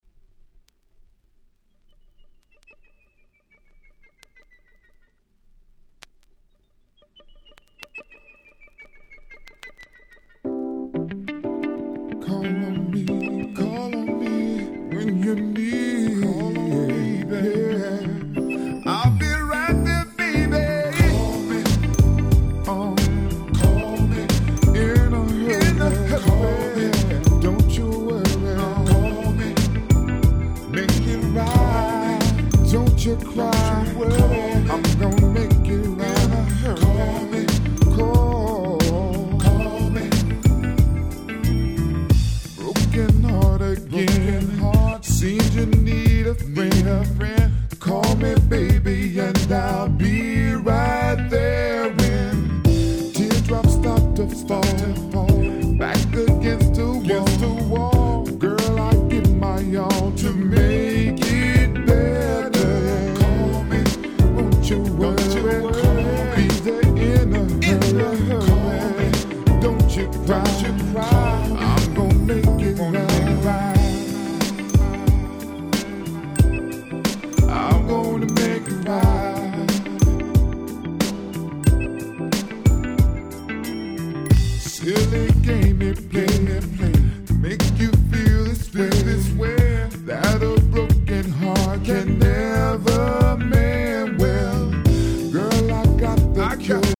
【Media】Vinyl 12'' Single
00' Nice R&B !!